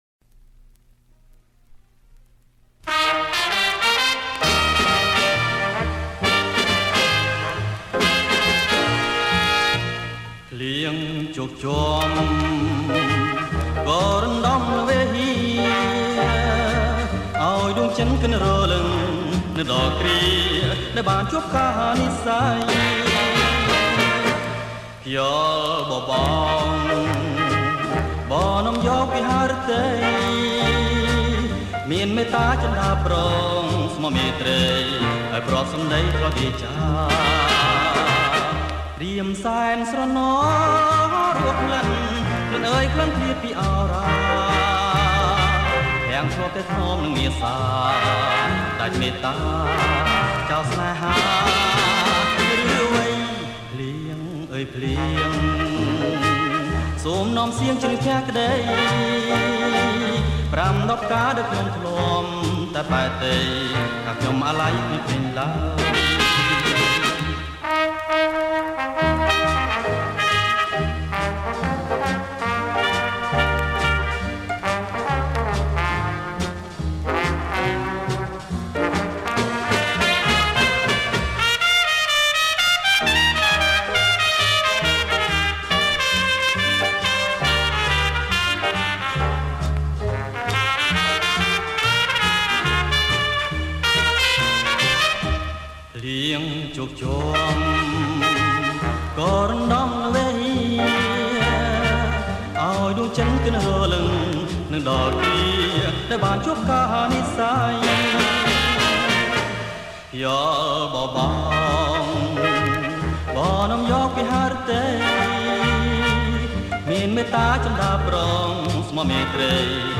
• ប្រគំជាចង្វាក់ Slow fox
ប្រគំជាចង្វាក់ Fox